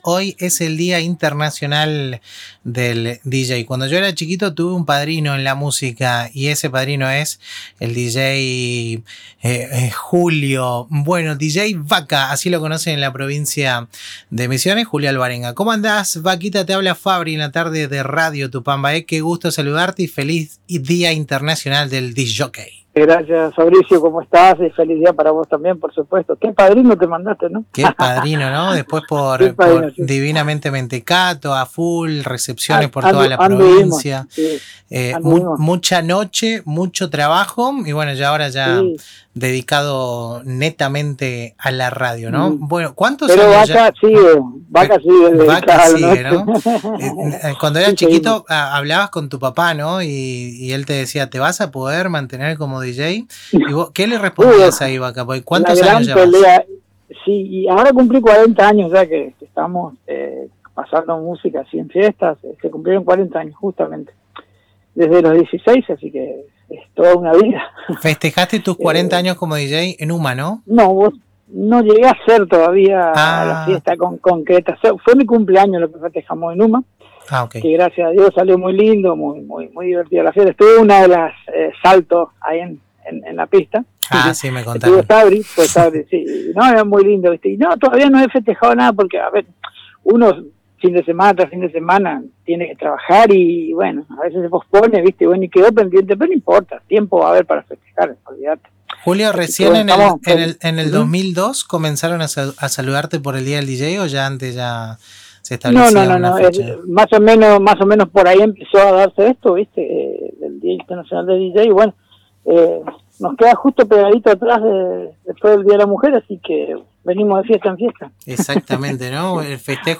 Durante la entrevista radial